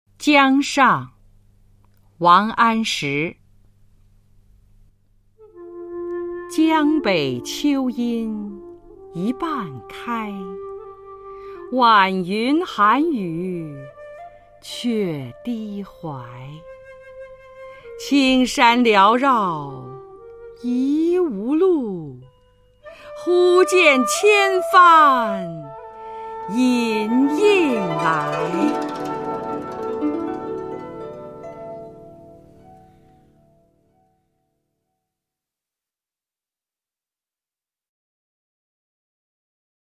《小学必背古诗文标准朗读》之十三